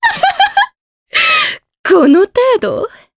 Maria-Vox_Victory_jp.wav